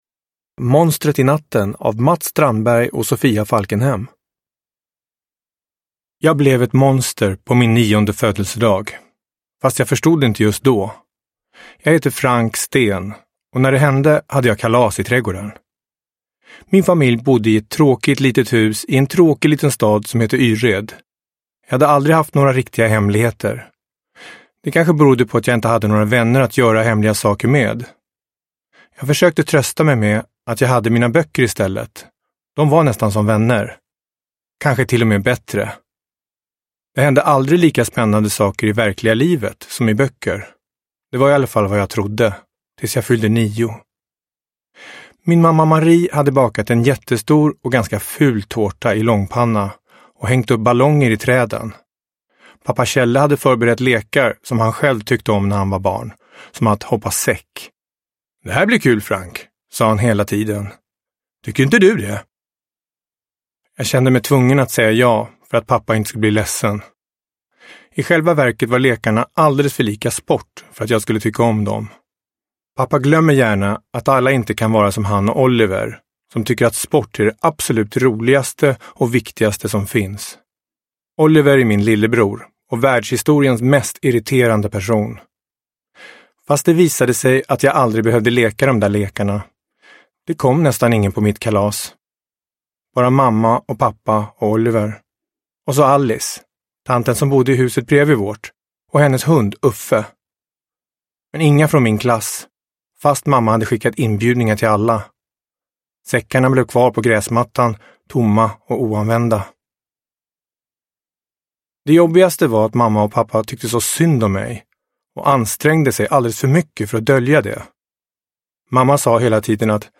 Monstret i natten (ljudbok) av Mats Strandberg